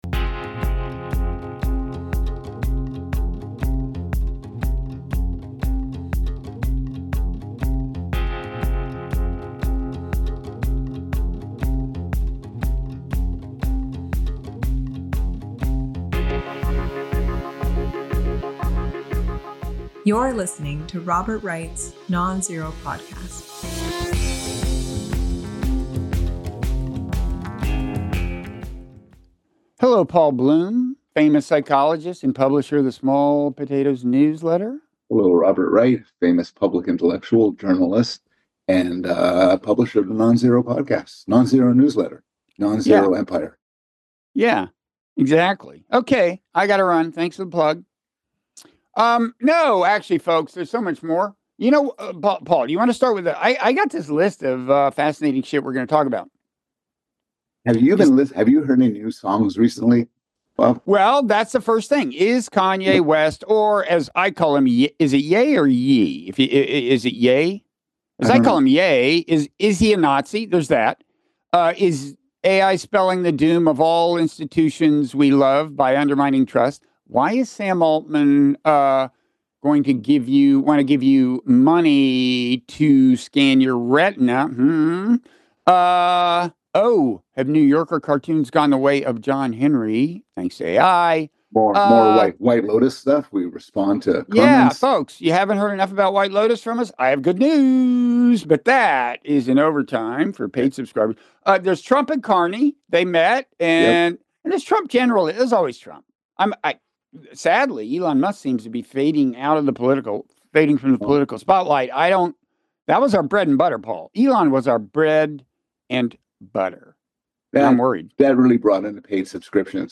Kanye, Trump, and Other Enigmas (Robert Wright & Paul Bloom) (Robert Wright interviews Paul Bloom; 16 May 2025) | Padverb